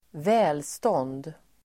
välstånd substantiv, prosperity Uttal: [²v'ä:lstå:nd] Böjningar: välståndet Synonymer: rikedom Definition: god ekonomi Exempel: frihet är viktigare än välstånd (liberty is more important than prosperity)